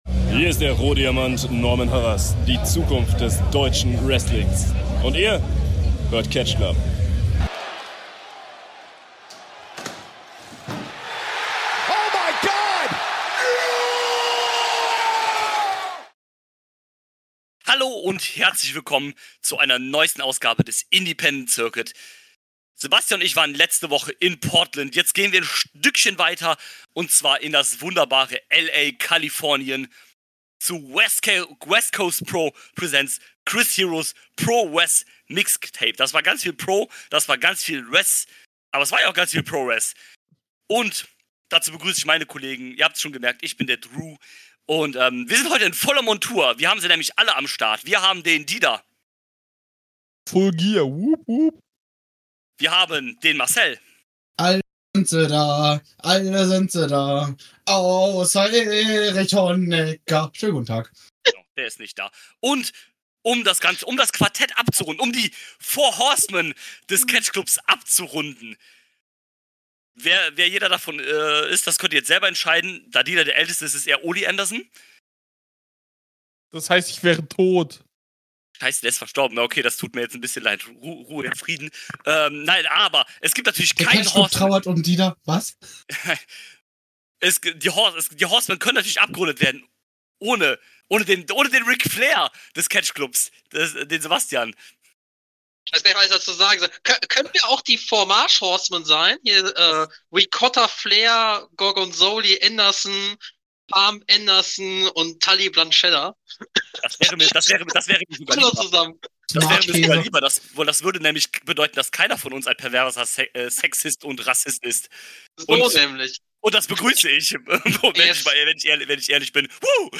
In der geballten 4er Runde besprechen Sie die Show und gehen auf alle Positiven und negativen Punkte ein.